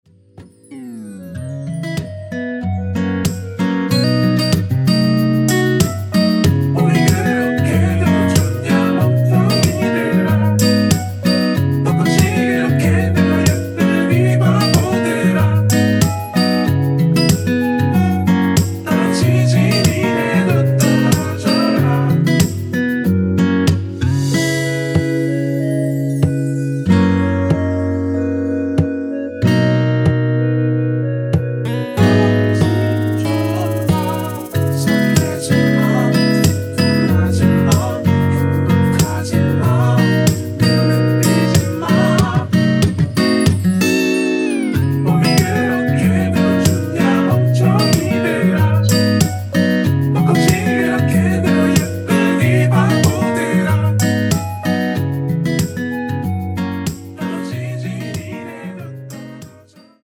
원키에서(-2)내린 멜로디와 코러스 포함된 MR 입니다.(미리듣기 확인)
앞부분30초, 뒷부분30초씩 편집해서 올려 드리고 있습니다.
중간에 음이 끈어지고 다시 나오는 이유는